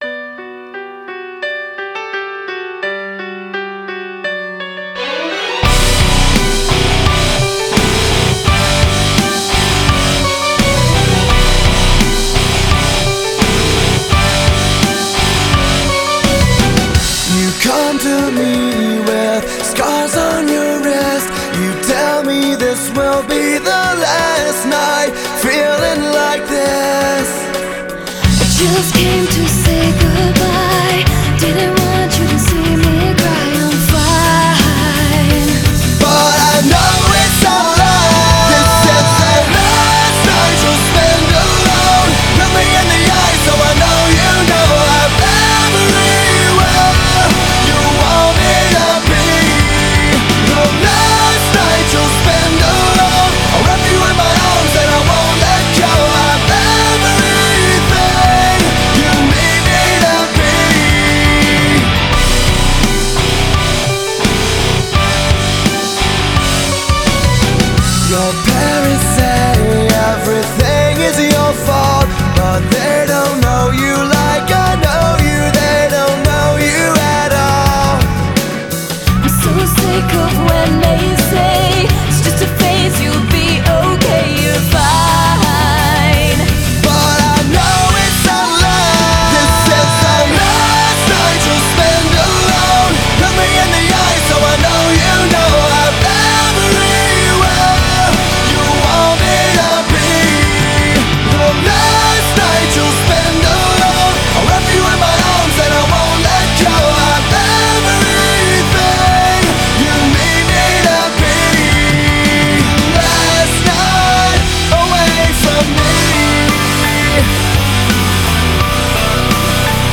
рок альтернатива
Категория: Альтернатива